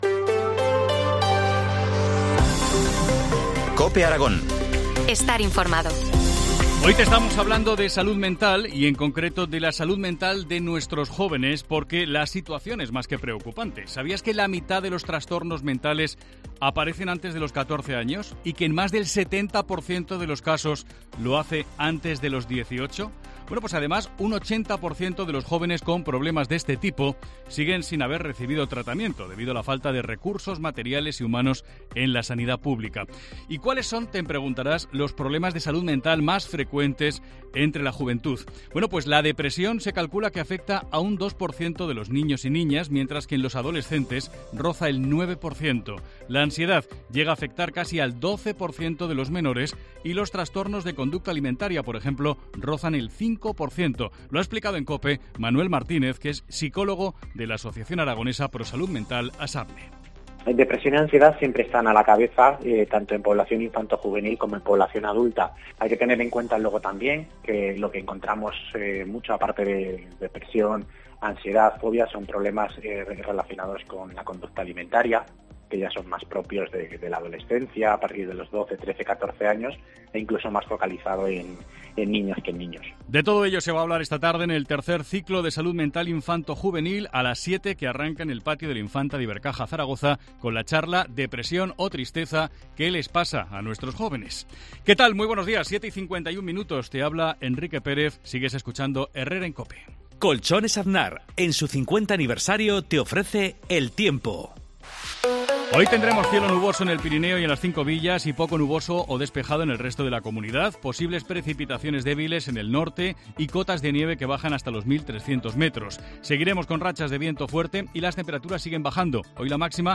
AUDIO: Titulares del día en COPE Aragón